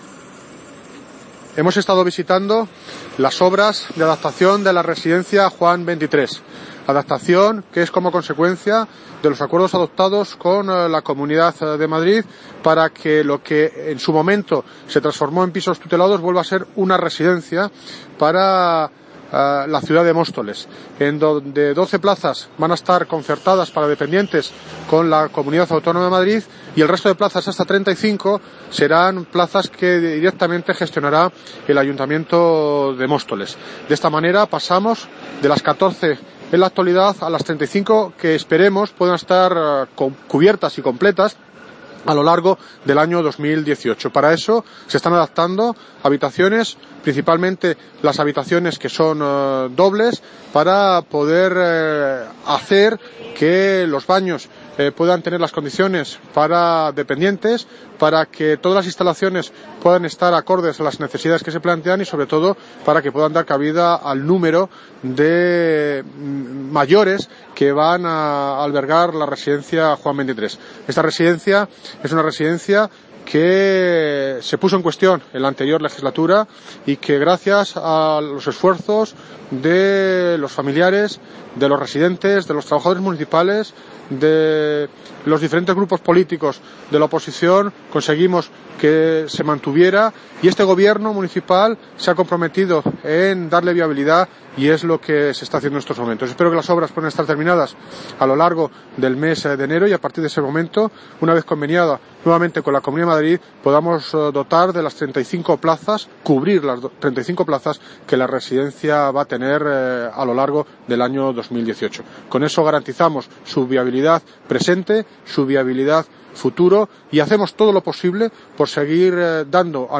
Sonido - David Lucas (Alcalde de Móstoles) sobre Visita obras Residencia Juan XXIII
David Lucas visita obras Residencia Juan XXIII.mp3